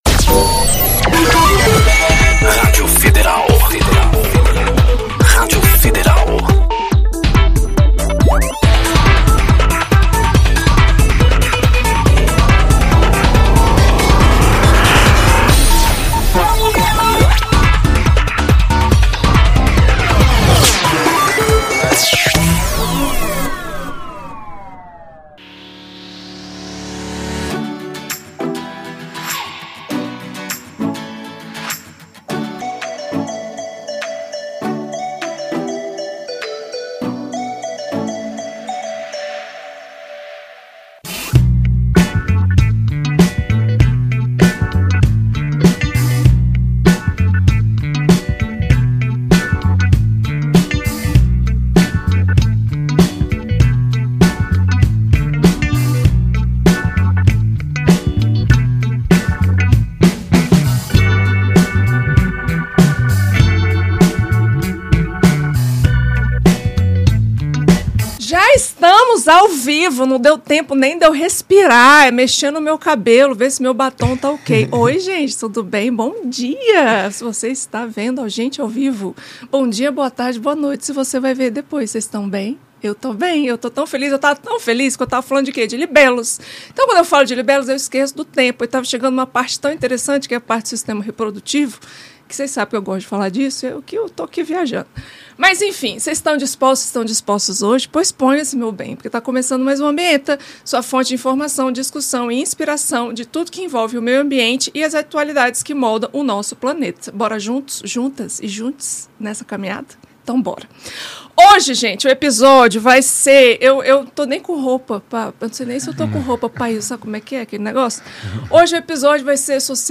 Neste episódio do Ambienta, recebemos o Deputado Distrital FÁBIO FELIX para uma conversa sobre como as pautas ambientais estão sendo tratadas na Câmara Legislativa do Distrito Federal. Vamos discutir a intersecção do meio ambiente com direitos humanos, justiça social, mobilidade urbana, e os direitos LGBTI+, feministas e antirracistas, todas pautas defendidas por Fábio ao longo de sua trajetória.